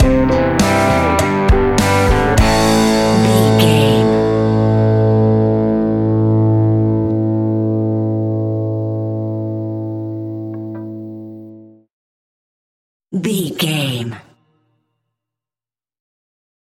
Epic / Action
Fast paced
Mixolydian
hard rock
heavy metal
blues rock
distortion
instrumentals
rock guitars
Rock Bass
Rock Drums
heavy drums
distorted guitars
hammond organ